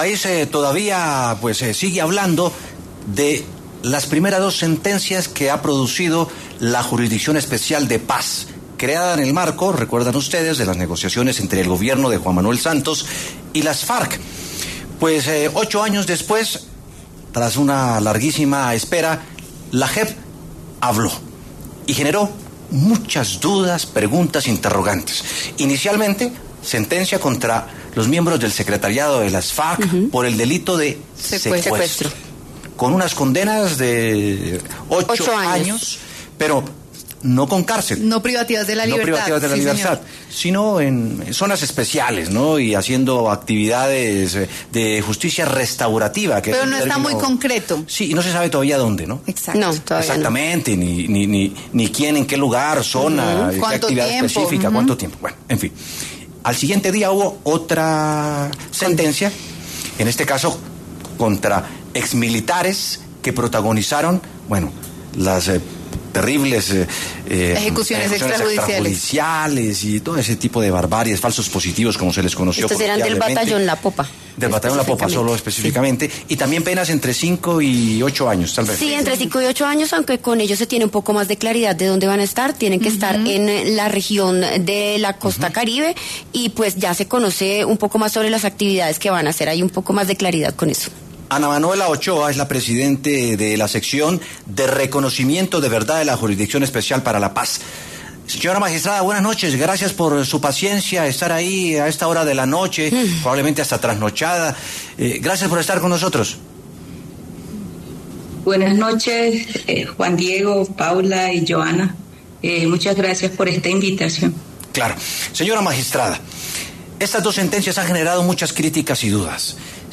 Ana Manuela Ochoa, presidente de la Sección de Verdad y Reconocimiento de la Jurisdicción Especial para la Paz, habló este lunes, 22 de septiembre, en los micrófonos de W Sin Carreta, para referirse a las sentencias que profirió la JEP contra el exsecretariado de las Farc y contra exmilitares miembros del Batallón de La Popa.